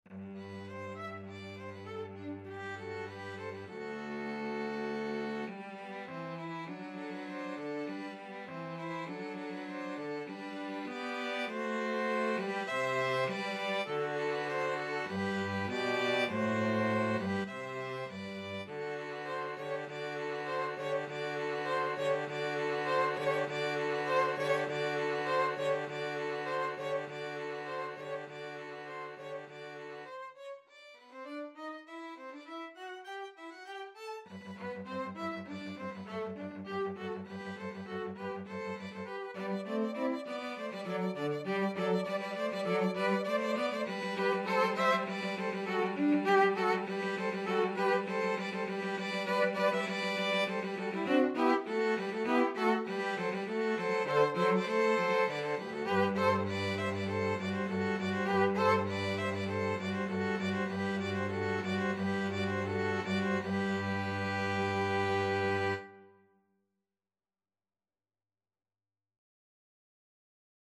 Free Sheet music for 2-Violins-Cello
G major (Sounding Pitch) (View more G major Music for 2-Violins-Cello )
2/4 (View more 2/4 Music)
Classical (View more Classical 2-Violins-Cello Music)